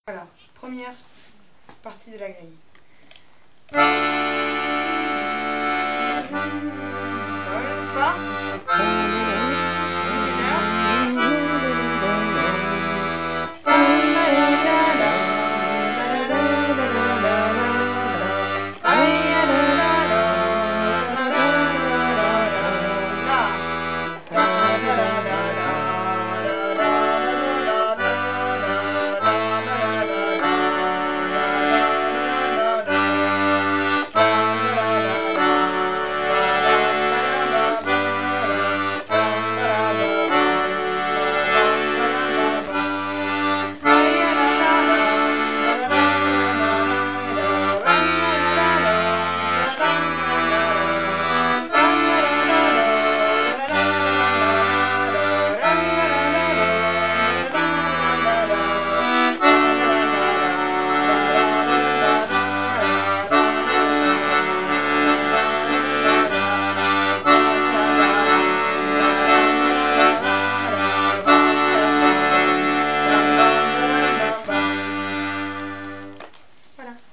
l'atelier d'accordéon diatonique
travail sur Juokin Toinen Jenkka, scottish en cortège finlandaise
voix 1 et 2 et l'accompagnement rythmique